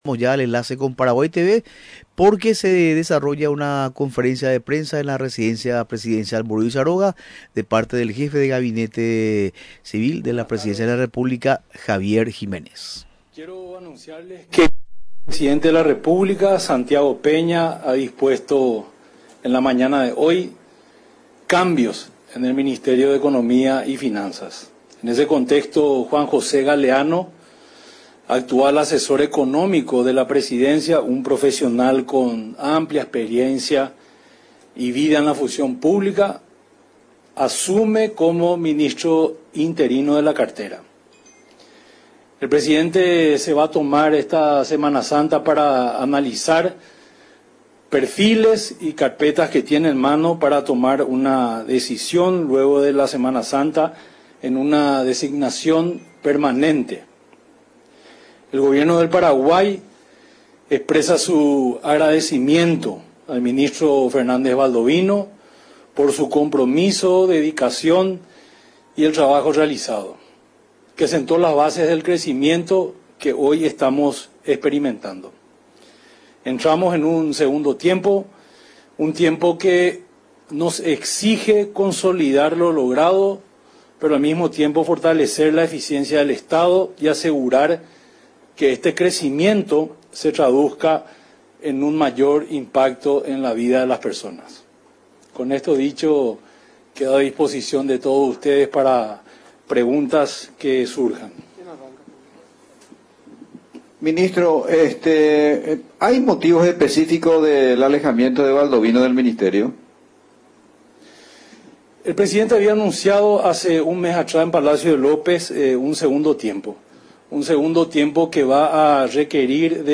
En rueda de prensa, realizada en la Residencia Presidencial de Mburuvichá Róga, explicó que el jefe de Estado, se tomará esta Semana Santa para analizar perfiles y carpetas que tiene en mano para tomar una decisión.